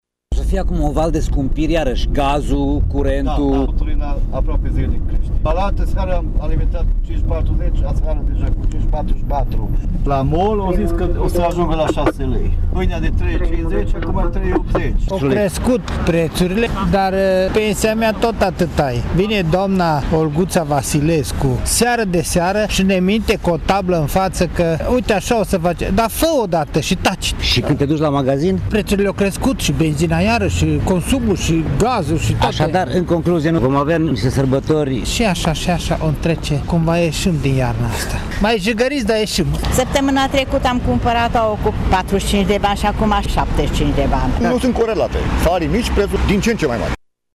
Oamenii sunt revoltați de faptul că tot mai multe alimente s-au scumpit în ultima perioadă, iar creșterile salariale anunțate sunt anulate de alte măsuri fiscale luate de guvernanți: